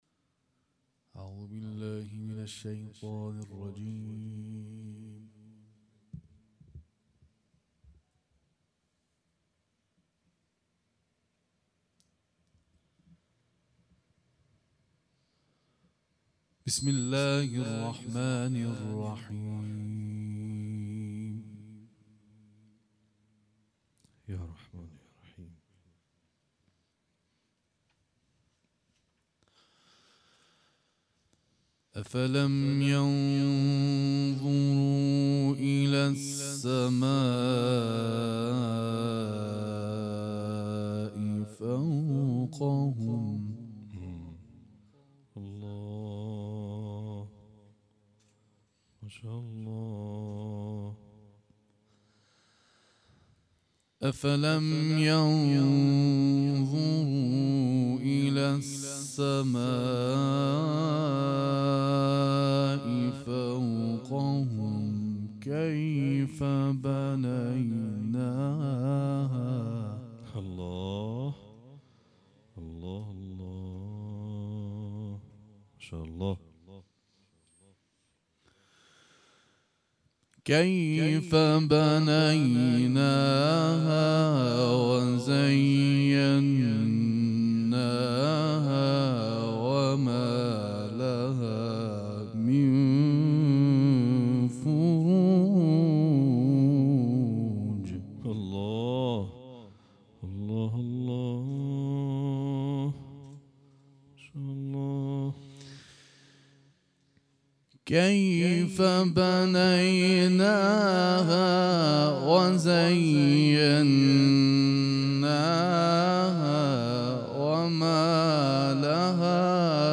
کرسی تلاوت در کرج برگزار شد
گروه جلسات و محافل: کرسی تلاوت با حضور قاریان ممتاز در شهر کرج برگزار شد.